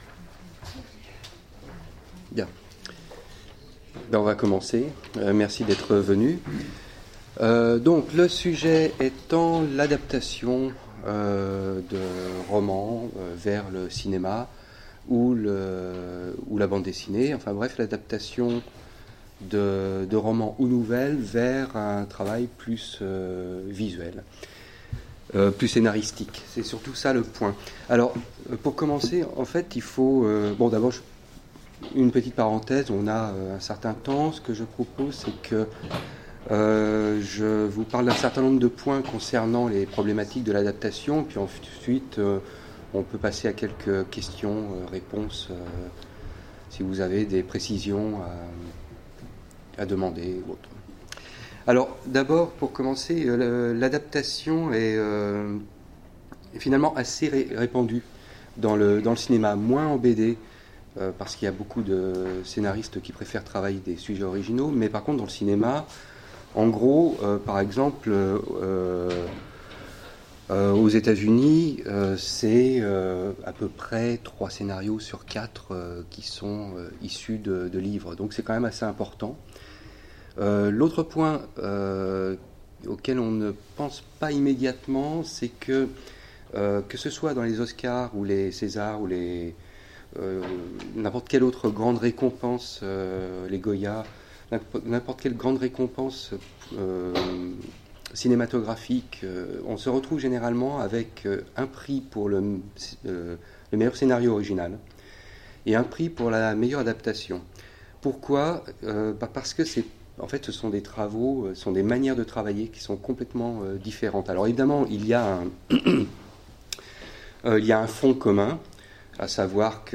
Utopiales 2015 : Cours du soir
Mots-clés Ecriture Conférence Partager cet article